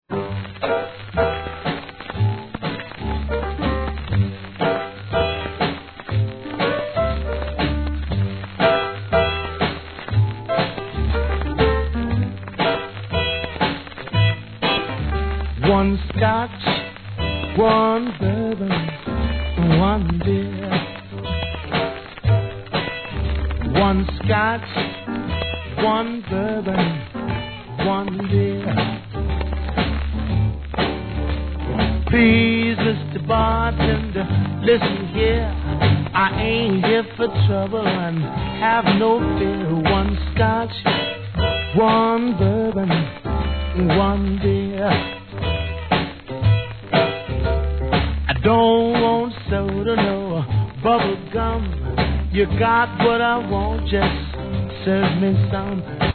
SOUL/FUNK/etc...